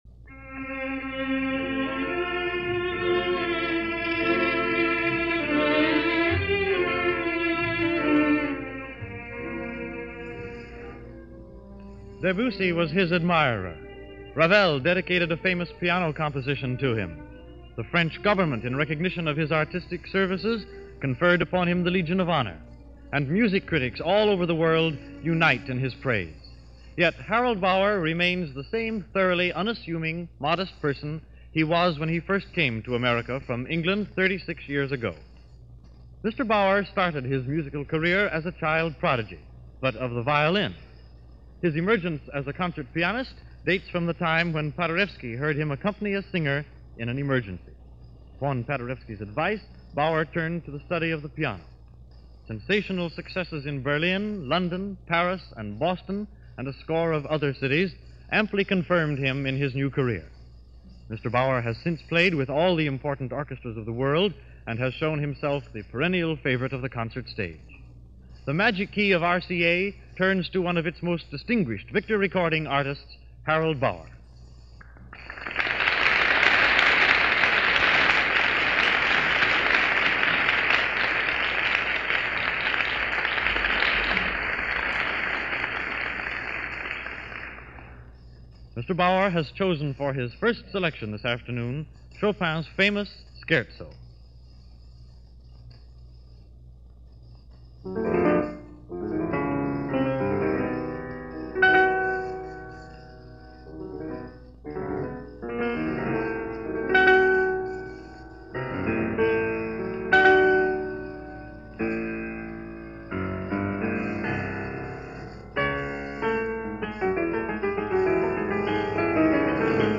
Harold Bauer plays music of Chopin - RCA - The Magic Key Program - May 26, 1936 - NBC Red Network - Past Daily Weekend Gramophone.
Going into the early 20th century this week for a rare broadcast recital by the legendary late 19th/early 20th century pianist Harold Bauer.